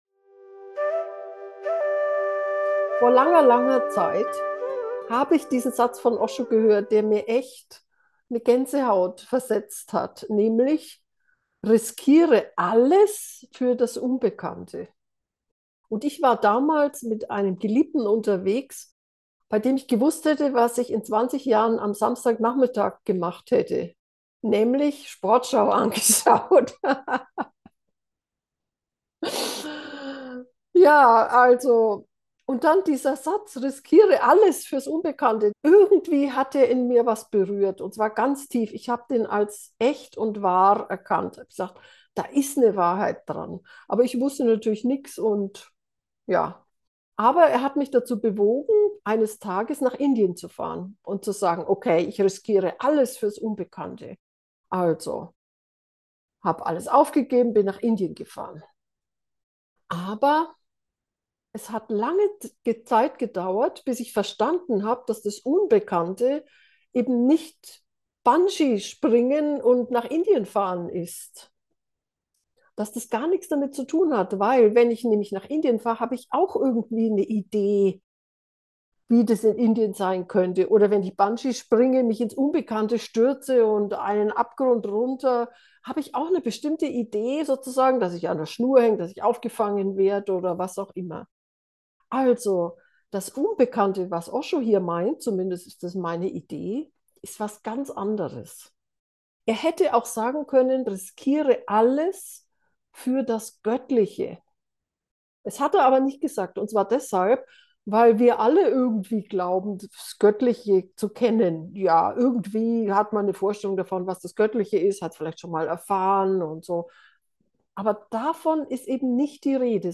riskiere-alles-fuers-unbekannte-meditation.mp3